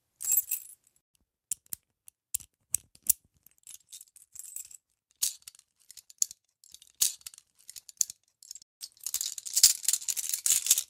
handcuffoff.ogg